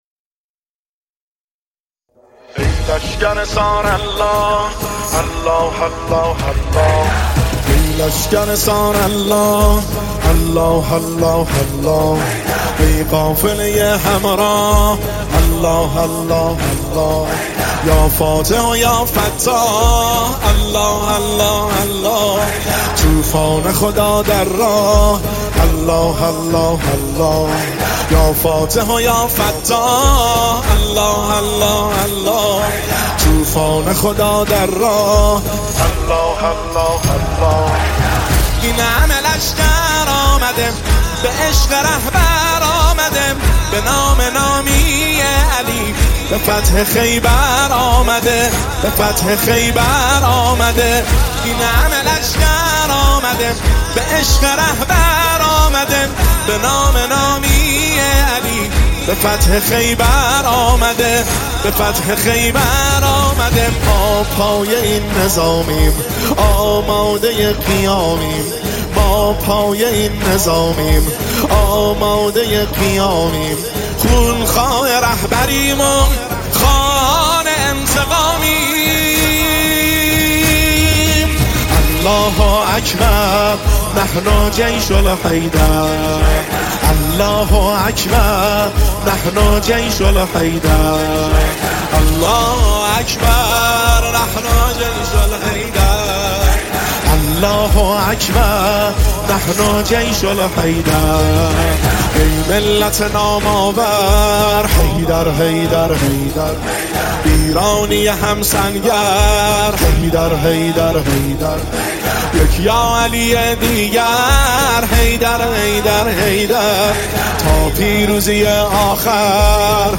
مداحی حماسی